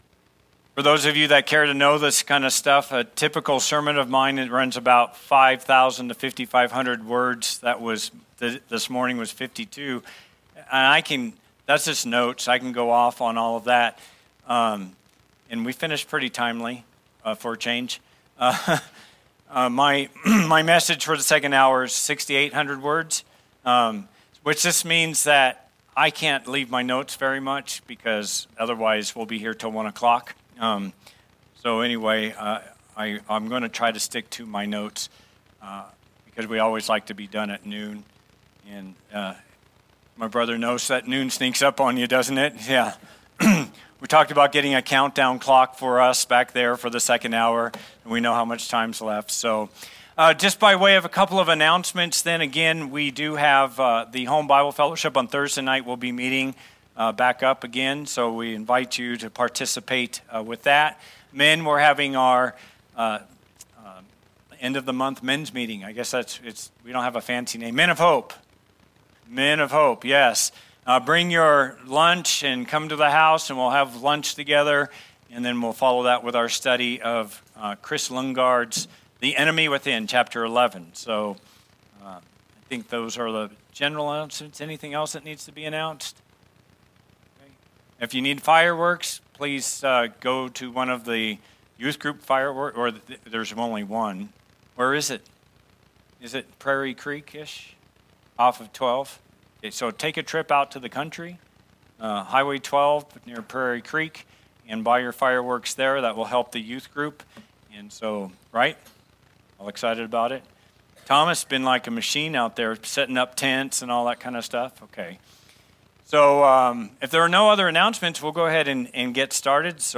Sermons | Hope CBC